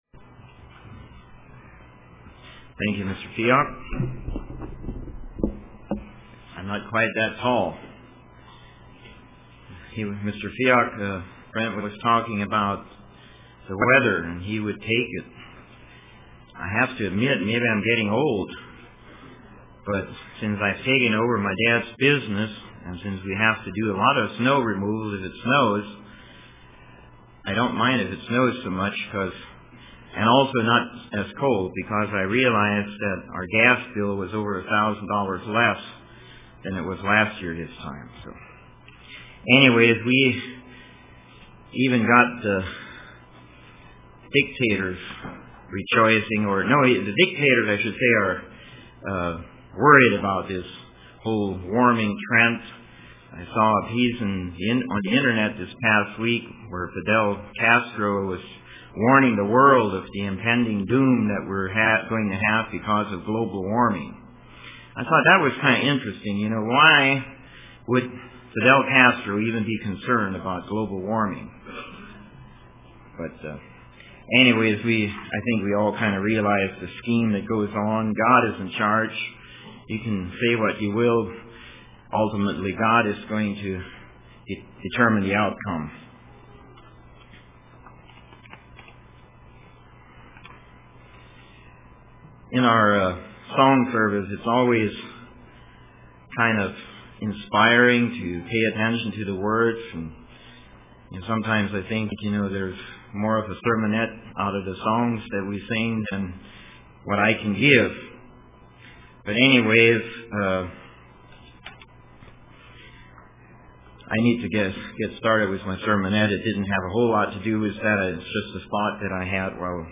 Print Lessons From the Children UCG Sermon Studying the bible?